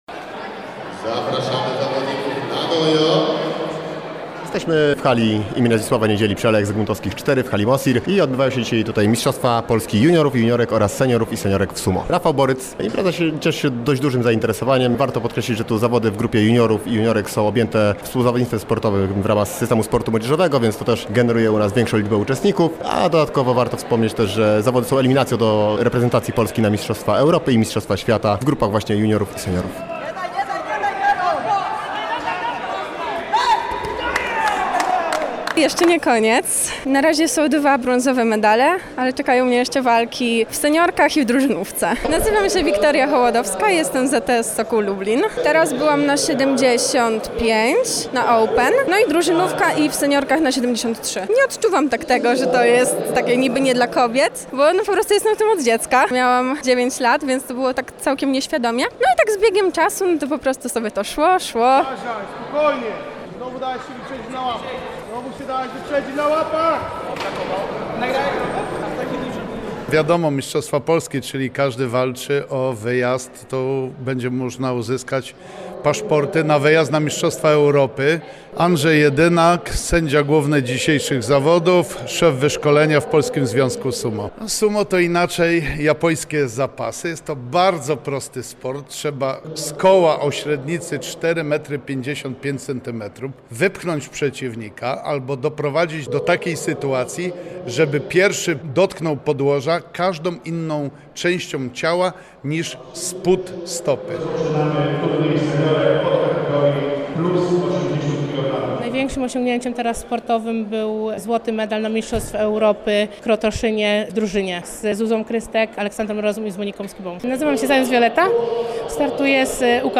W ostatnią sobotę hala MOSiR w Lublinie gościła Mistrzostwa Polski Seniorów i Juniorów w tej niezwykłej dyscyplinie.